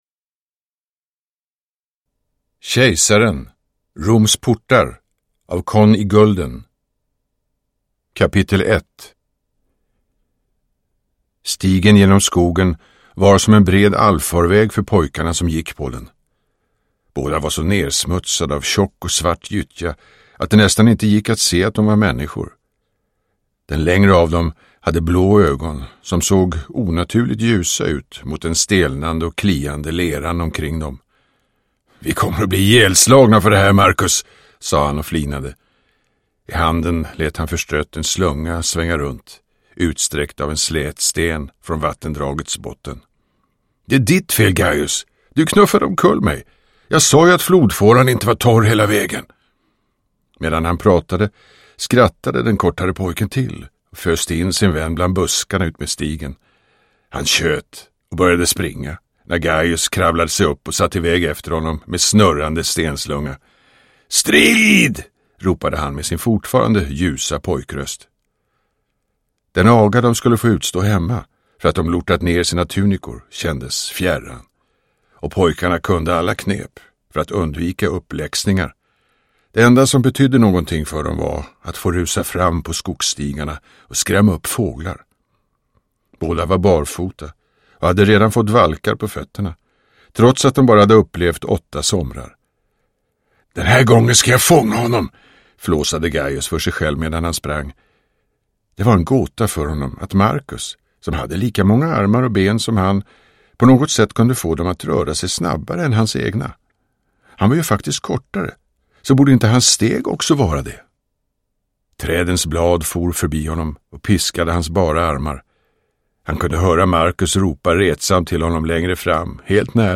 Roms portar : Kejsaren I – Ljudbok – Laddas ner
Uppläsare: Torsten Wahlund